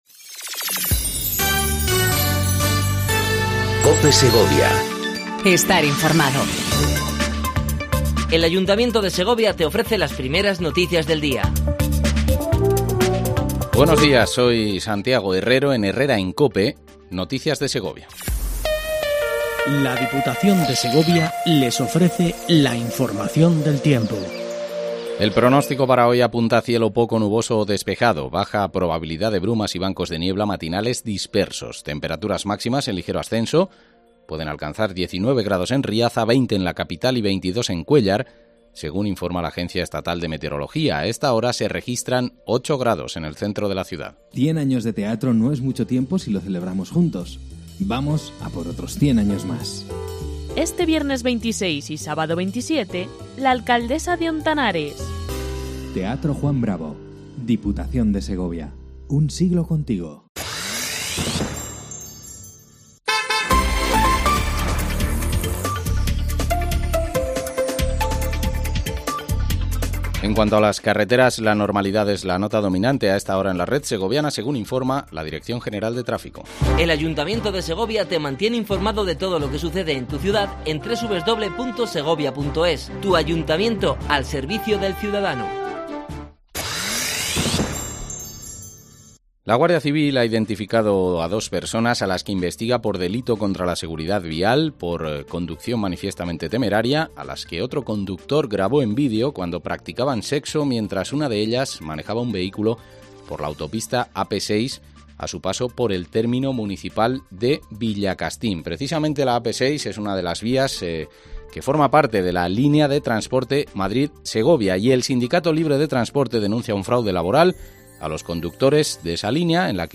AUDIO: Primer informativo local en cope segovia
INFORMATIVO LOCAL